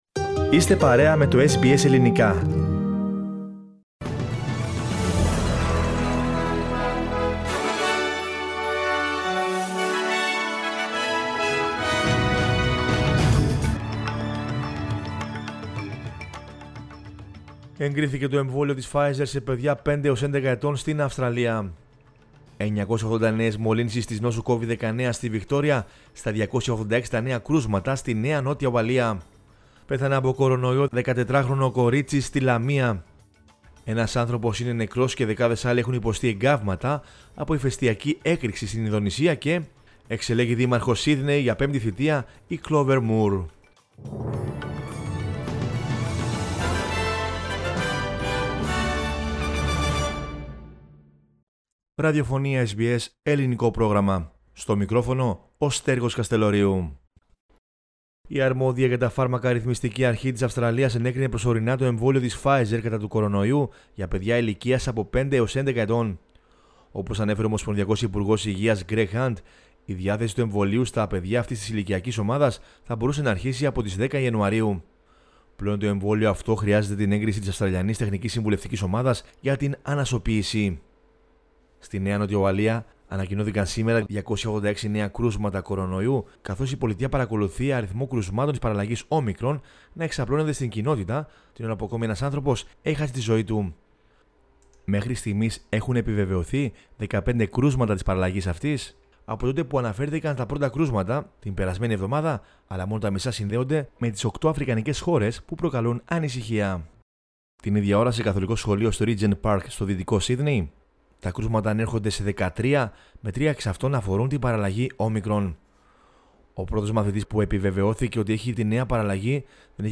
News in Greek from Australia, Greece, Cyprus and the world is the news bulletin of Sunday 5 December 2021.